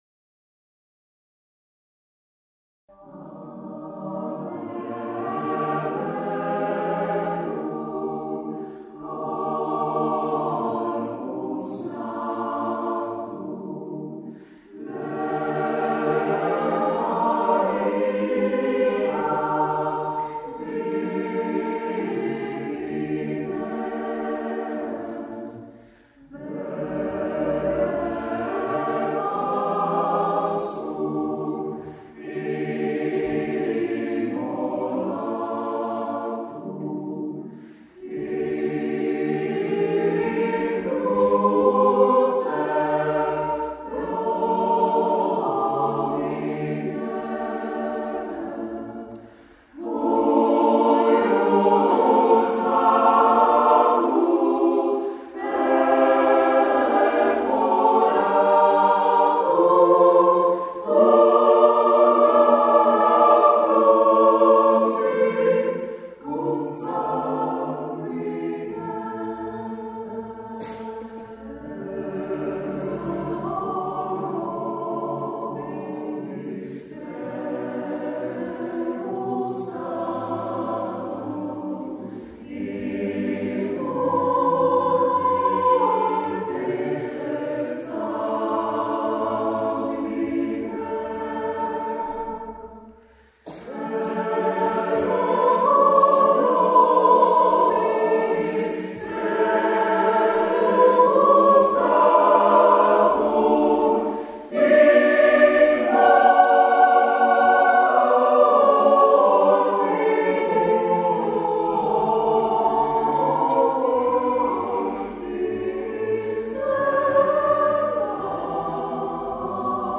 Zvuková ukázka z vystoupení v kostele Panny Marie v Železné Rudě